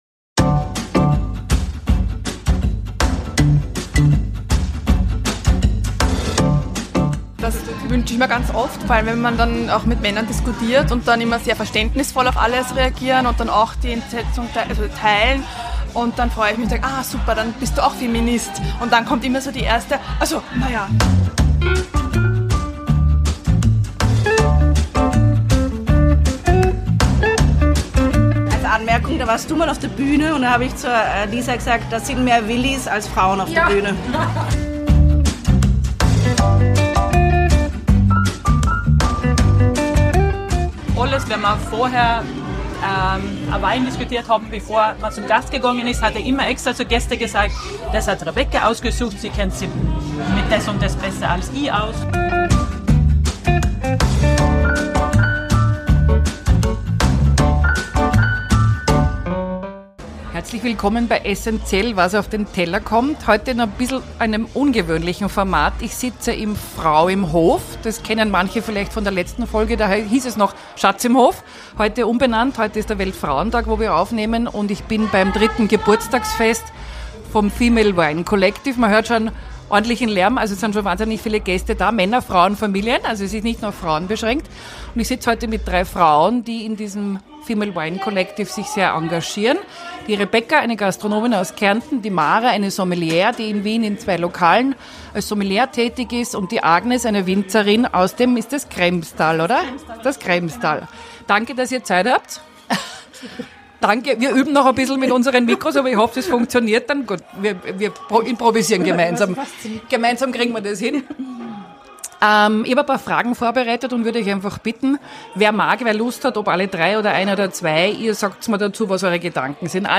Noch immer braucht es Initiativen wie das Female Wine Collective, um eine gleiche Sichtbarkeit und Chancengerechtigkeit von Frauen und Männern in Gastronomie und Weinbau zu schaffen. An welchen Themen arbeitet die Protagonistinnen und wie gelingt es, die Männer in machtvollen Positionen für die Sache zu aktivieren? Ein Gespräch am Weltfrauentag